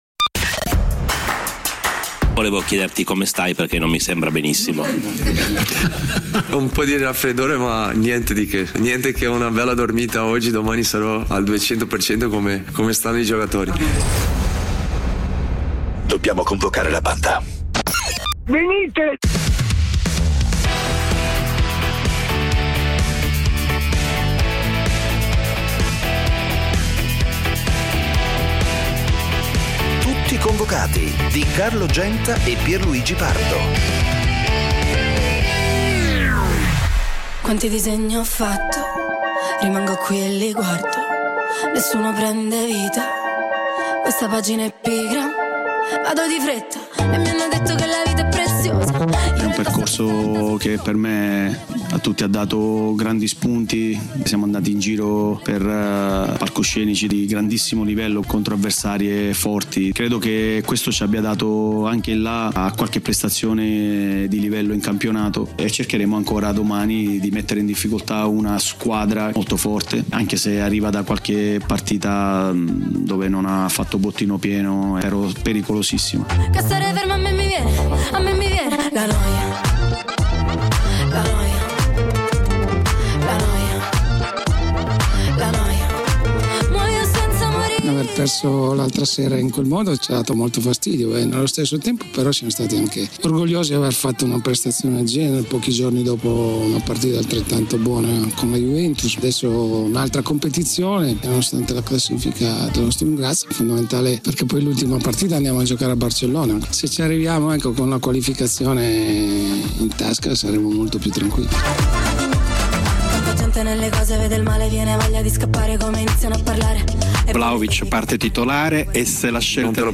Le voci e i suoni del calcio. Una lettura ironica e coinvolgente degli avvenimenti dell'attualità sportiva, senza fanatismi e senza tecnicismi. Commenti, interviste e soprattutto il dibattito con gli ascoltatori, che sono Tutti convocati.
Con imitazioni, tic, smorfie, scherzi da spogliatoio e ironia irriverente.